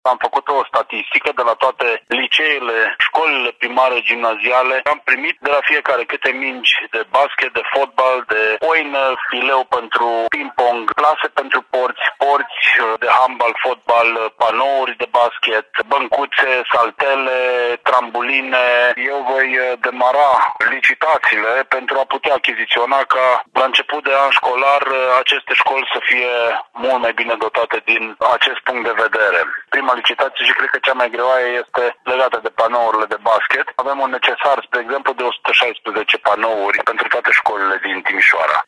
Viceprimarul Cosmin Tabără spune că a solicitat tuturor profesorilor de educație fizică necesarul de dotări, iar din bugetul local vor fi alocate achizițiilor, anul acesta, 700.000 lei.
Viceprimarul Cosmin Tabără spune că speră să obțină diferența de bani la rectificarea bugetară.